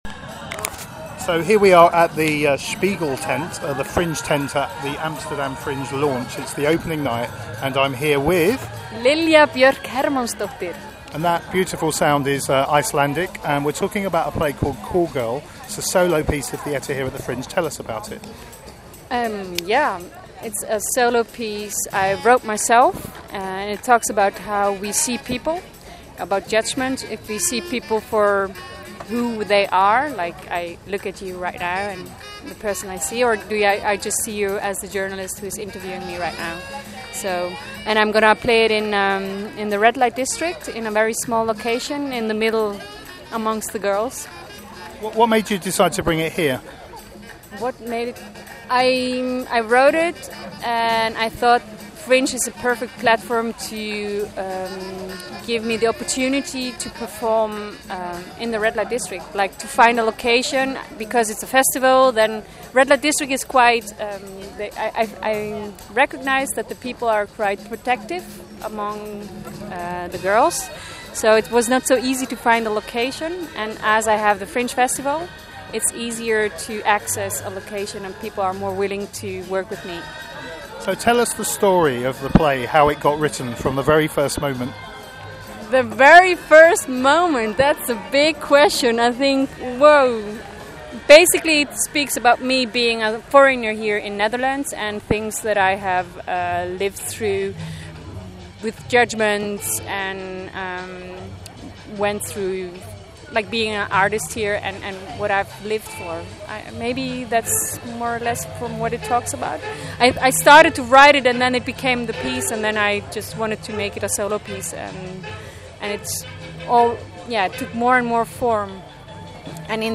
Amsterdam Fringe 2014, Audio Interviews